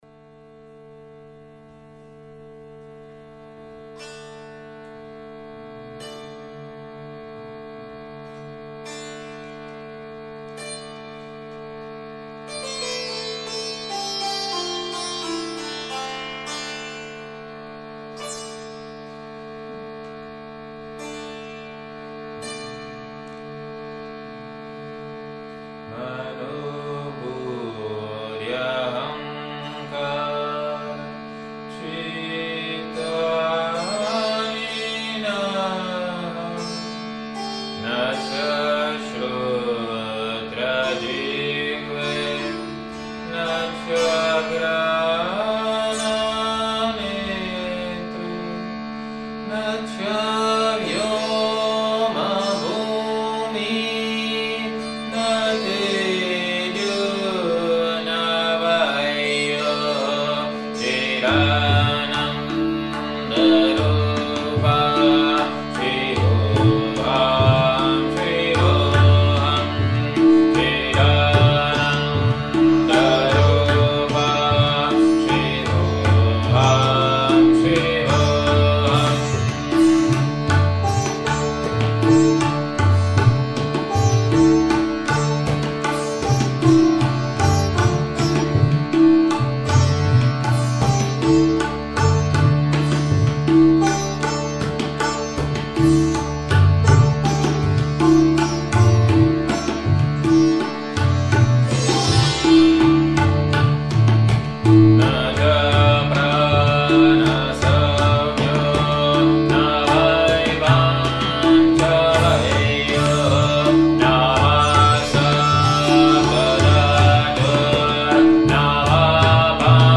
Kirtan, Prática e Sat Sanga
aqui excertos de um ensaio e de um concerto!